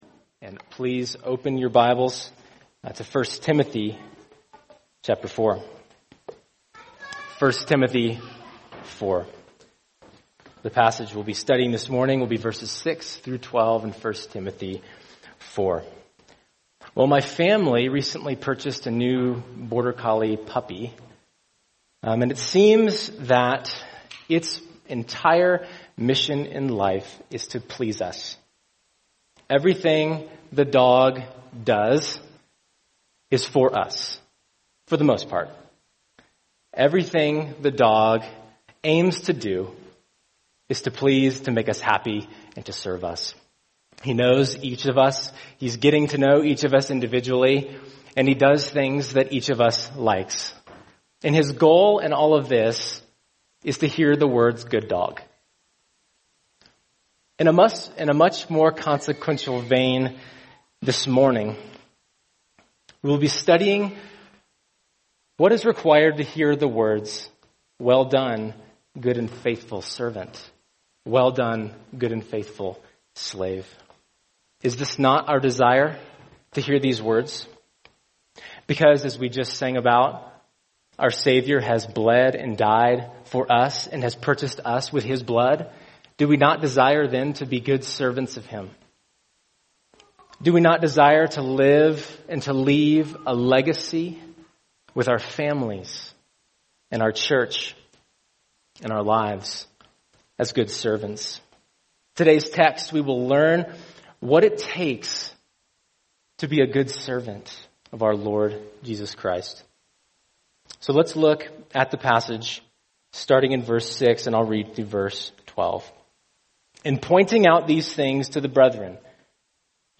[sermon] 1 Timothy 4:6-12 The Good Servant of Christ | Cornerstone Church - Jackson Hole